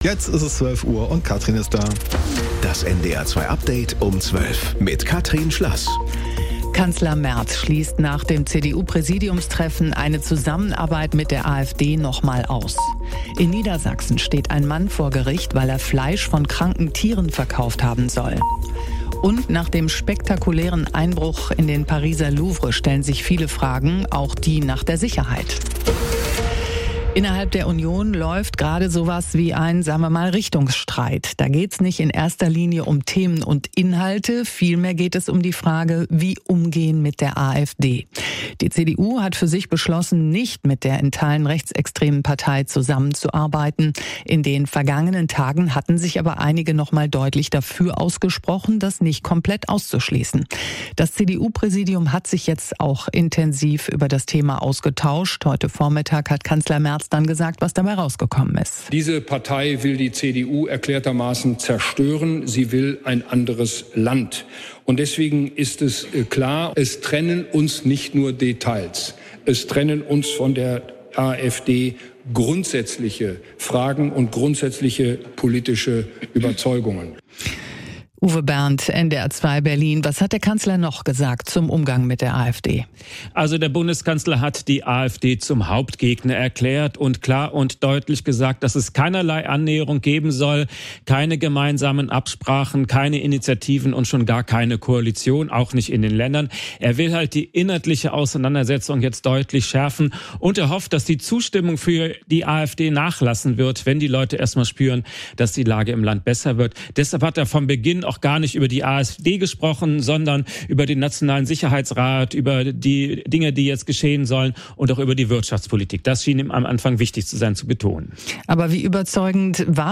NDR 2 Tägliche Nachrichten Nachrichten NDR News Kurier Um 12 Update Um 12